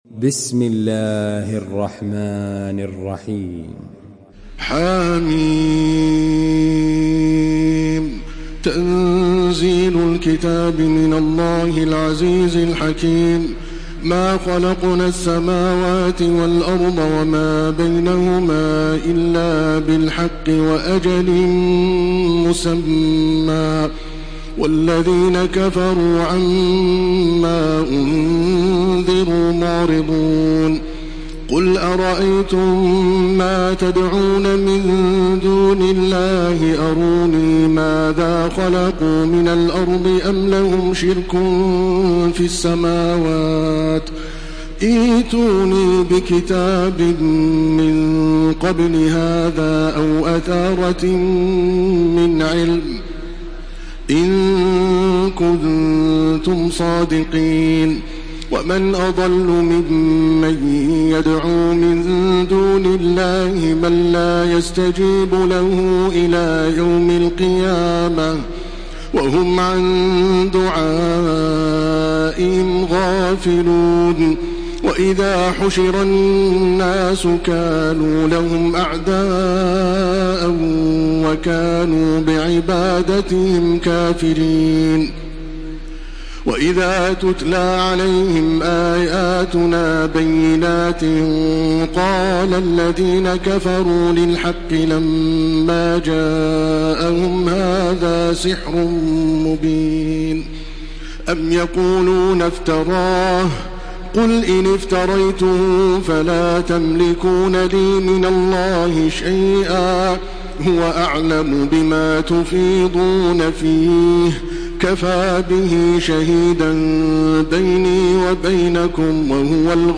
Surah আল-আহক্বাফ MP3 in the Voice of Makkah Taraweeh 1429 in Hafs Narration
Murattal